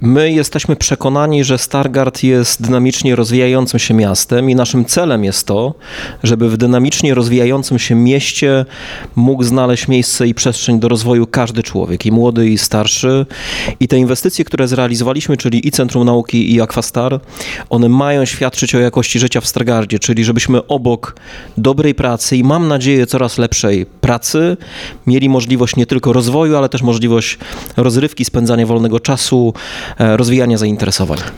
ROZMOWA DNIA
Dziś mieszkańcy Stargardu mogli po raz pierwszy skorzystać z oferty przygotowanej przez Aquastar. – Otwieramy takie obiekty, aby nasi mieszkańcy – obecni i przyszli – mieli możliwość atrakcyjnego spędzania czasu wolnego – mówił na naszej antenie prezydent Stargardu, Rafał Zając.